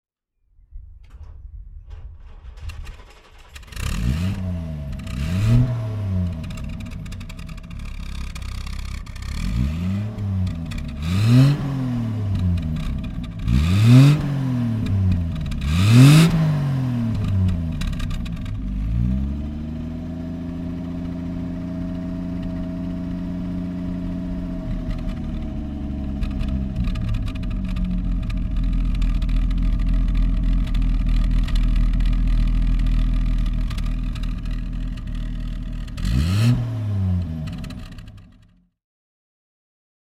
Rover P5 3 Litre Mark III (1967) - Starten und Leerlauf
Rover_3_Litre_Mark_III_1967.mp3